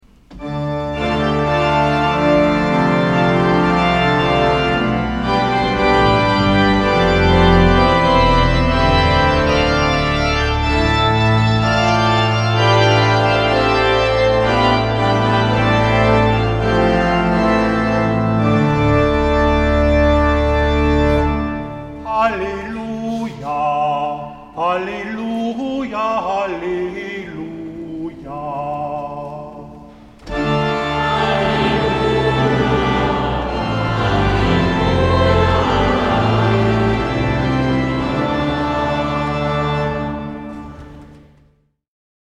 Musikalische Impressionen während der heiligen Messe zur Schlussoktav am Sonntag, den 12. September 2010